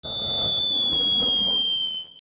/ C｜環境音(人工) / C-45 ｜花火
打ち上げ花火が空に登る 01
キーーイン